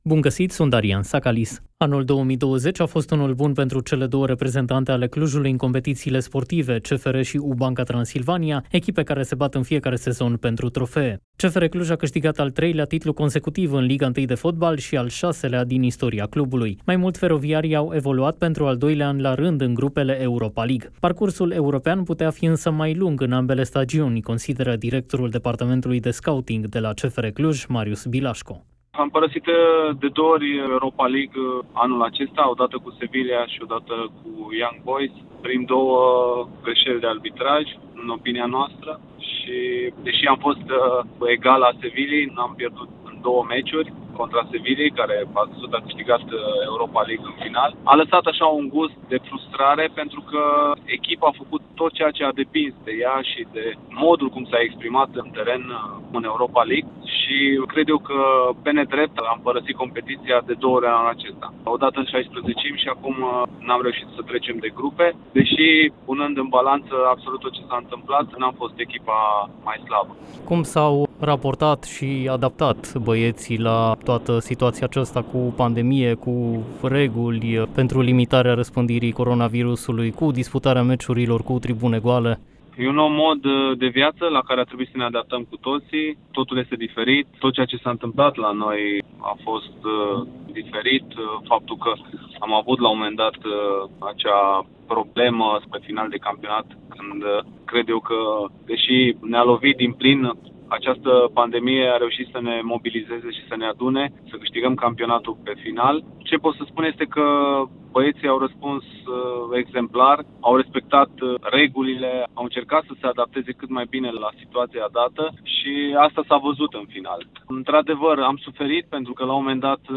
Cei doi au vorbit